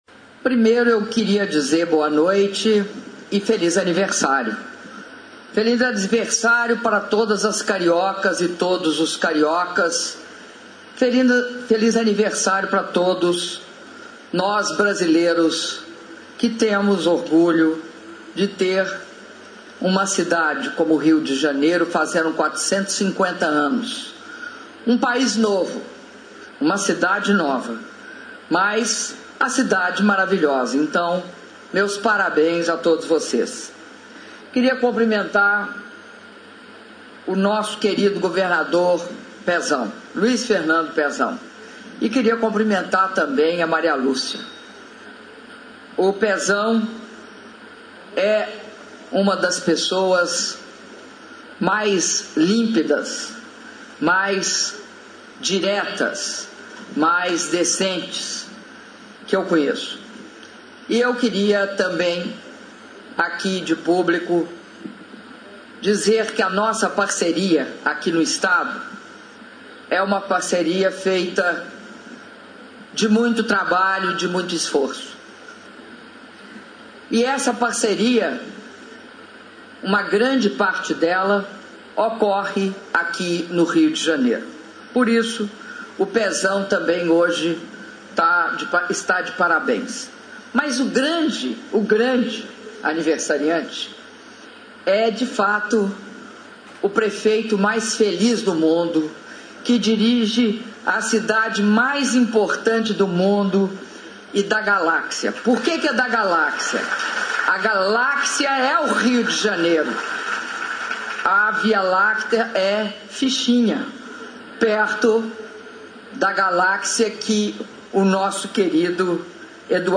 Áudio do discurso da Presidenta da República, Dilma Rousseff, durante a cerimônia de aniversário dos 450 anos da cidade do Rio de Janeiro - Rio de Janeiro/RJ (15min13s)